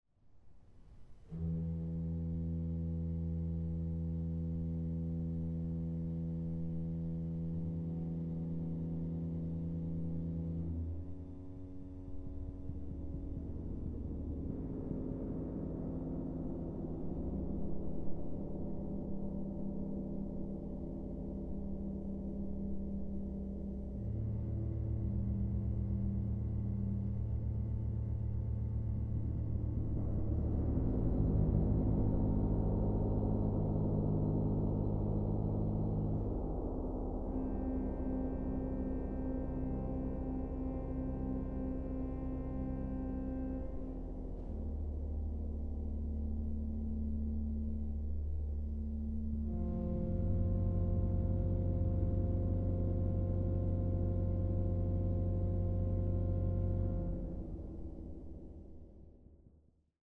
Organ
Percussion
Gong
Recording: Het Orgelpark, Amsterdam, 2023